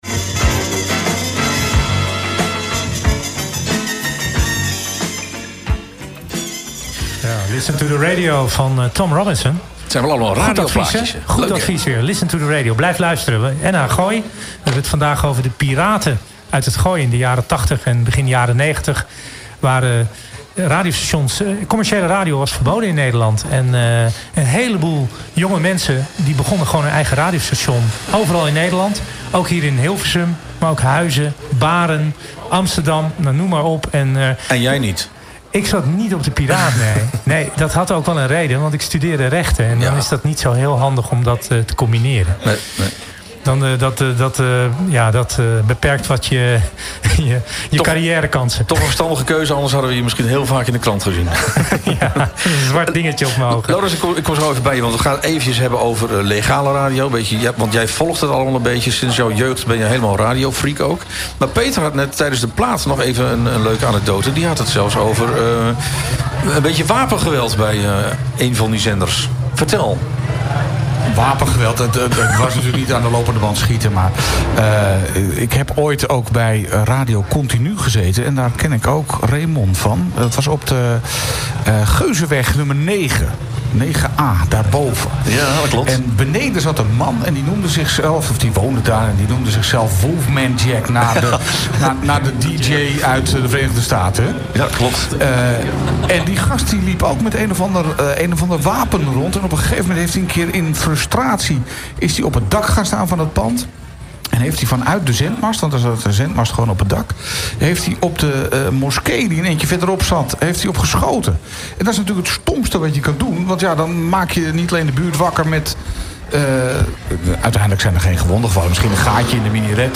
Een interview vanuit de speciale uitzending vanaf de Kerkbrink in Hilversum. In het kader van de Dutch Media Week en 100 Jaar Radio gingen wij terug in de tijd naar de momenten, waar illegale zenders in ‘t Gooi overal de kop op staken en razend populair waren. Maar hoe zit het met de legale radiostations.